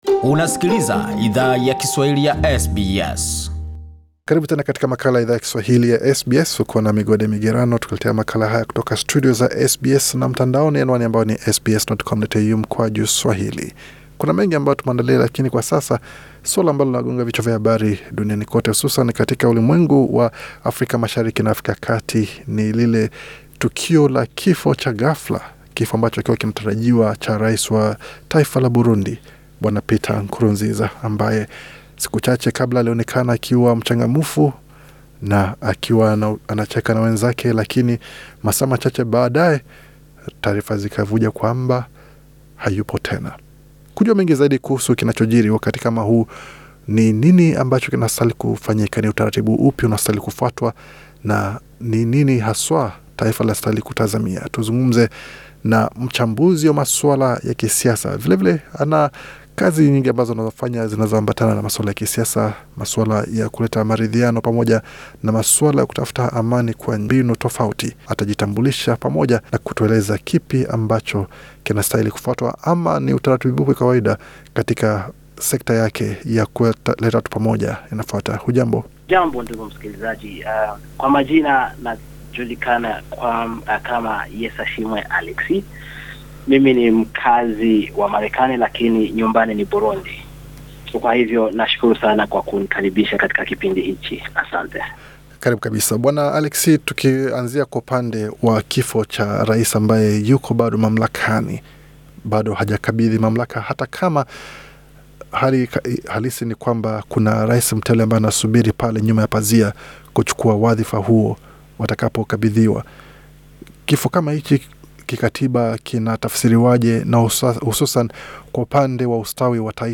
Katika mazungumzo na Idhaa ya Kiswahili ya SBS